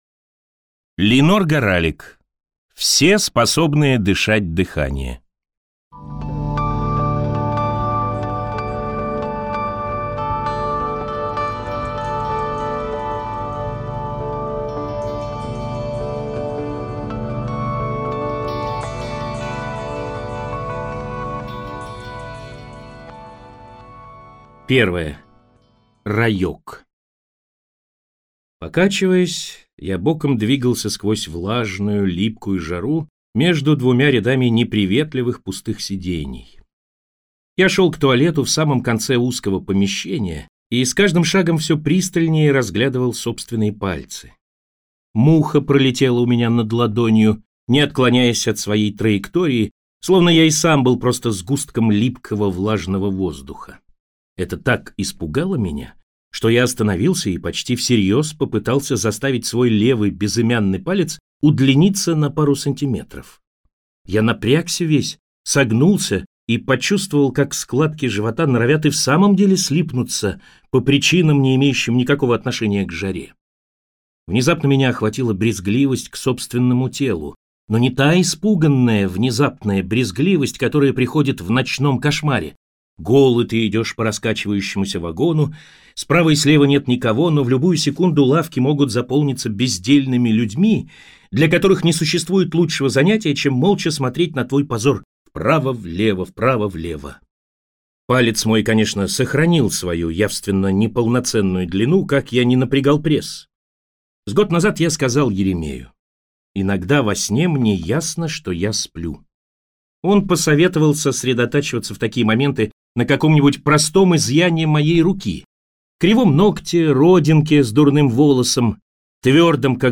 Аудиокнига Все, способные дышать дыхание | Библиотека аудиокниг